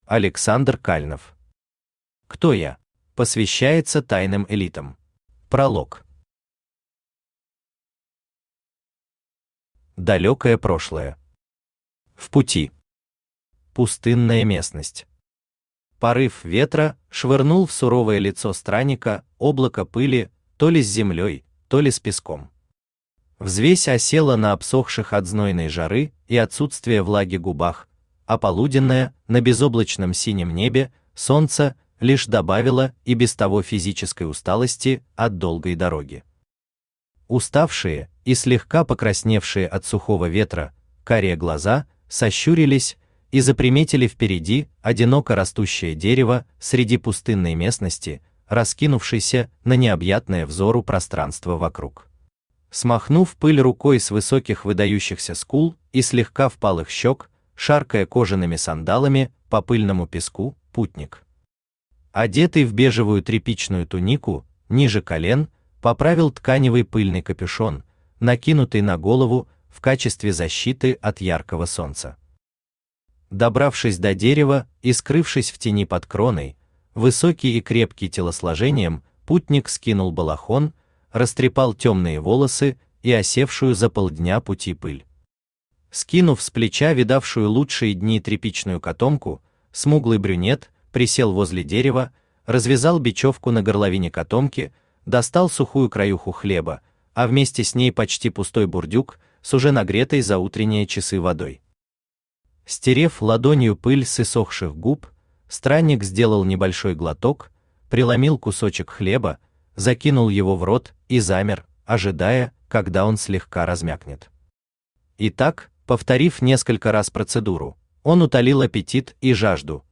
Аудиокнига Кто Я?
Автор Александр Александрович Кальнов Читает аудиокнигу Авточтец ЛитРес.